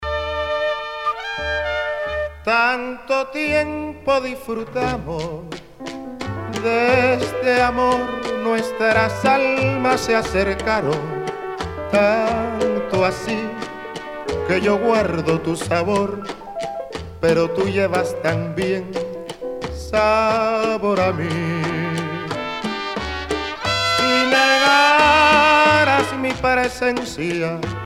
danse : boléro
Pièce musicale éditée